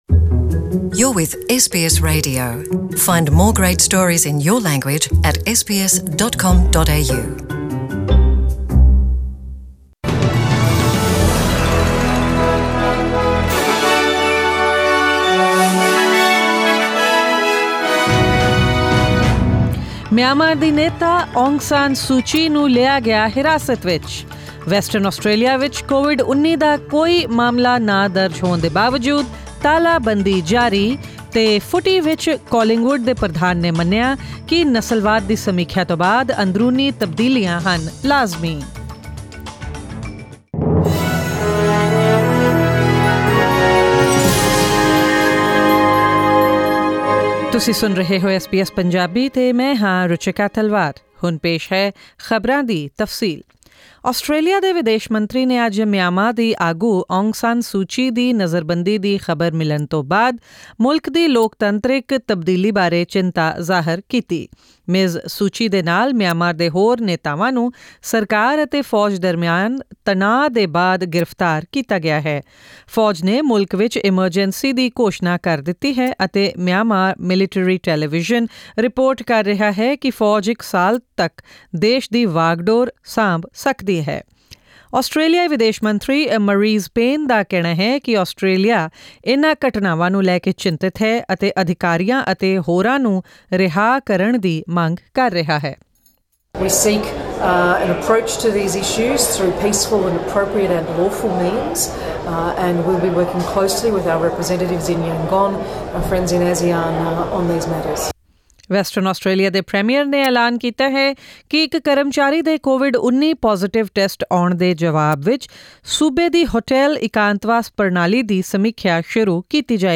Western Australian's Premier has declared a review will begin into the state's hotel quarantine system in response to a worker becoming infected. This and other major national and international news stories of today; sports, currency exchange rates and the weather forecast for tomorrow in tonight's bulletin.